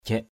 /ʥɛʔ/